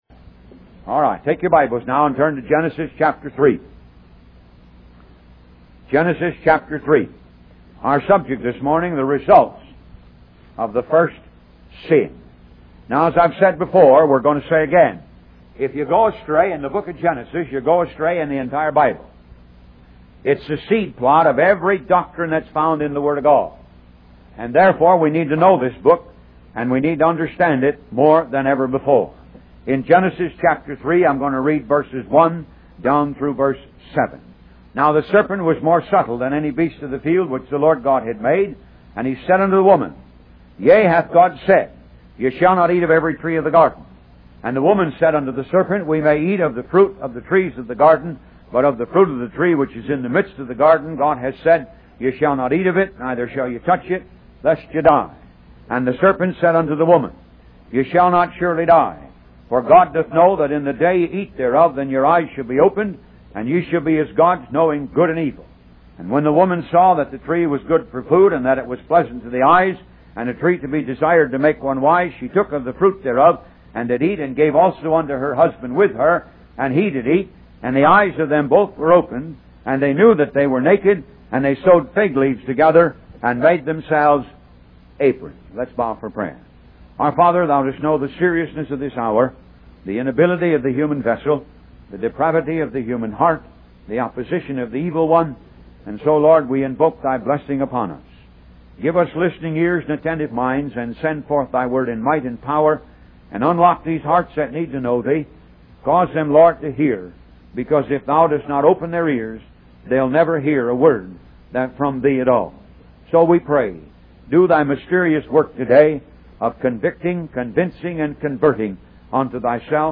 Talk Show Episode, Audio Podcast, Moga - Mercies Of God Association and Results Of The First Sin on , show guests , about Results Of The First Sin, categorized as Health & Lifestyle,History,Love & Relationships,Philosophy,Psychology,Christianity,Inspirational,Motivational,Society and Culture